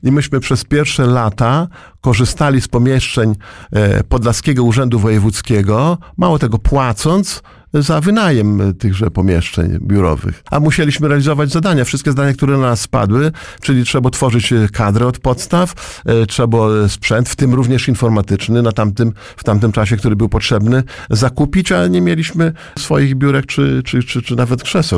Starosta mówił na naszej antenie o początkach powiatu, który przez pierwsze lata nie miał nawet swojej siedziby.